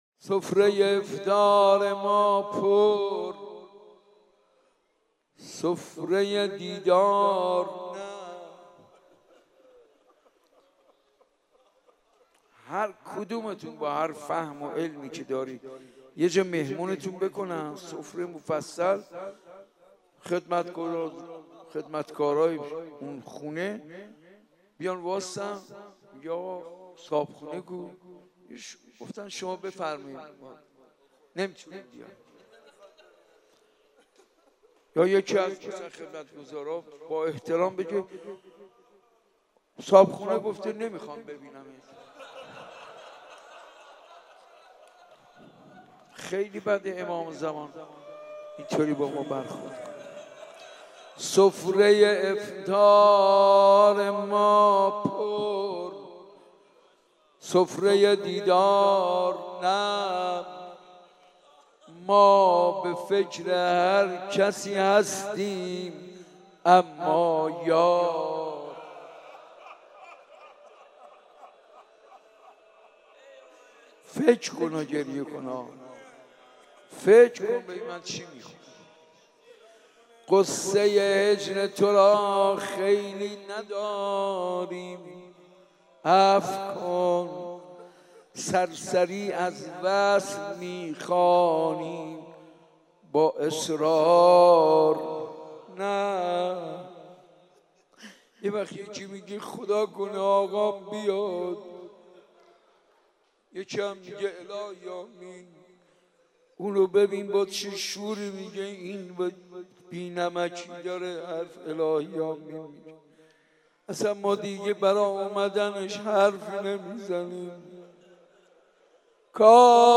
شب دوازدهم رمضان : روضه ی حضرت امام سجاد علیه السلام
سفره ی افطار ما پر سفره دیدار نه | مناجات